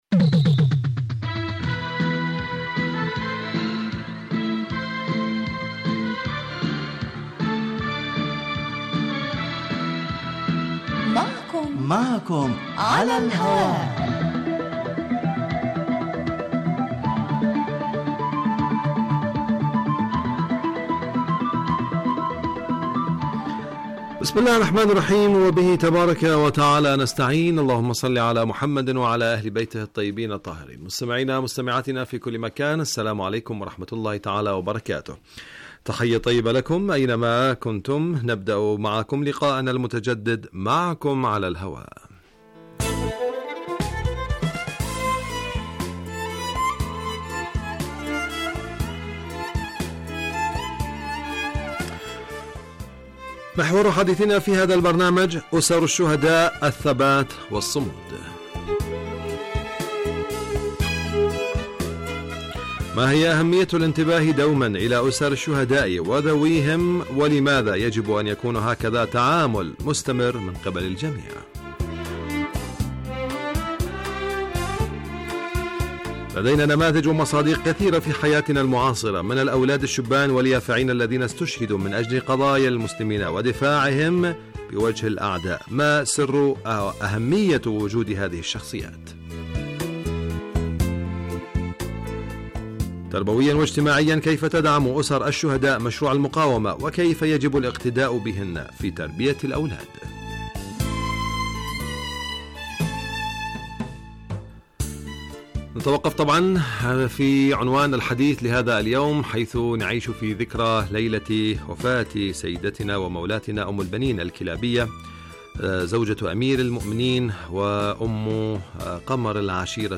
من البرامج المعنية بتحليل القضايا الاجتماعية في دنيا الإسلام و العرب و من أنجحها الذي يلحظ الكثير من سياسات القسم الاجتماعي بصورة مباشرة علي الهواء وعبر الاستفادة من رؤي الخبراء بشان مواضيع تخص هاجس المستمعين.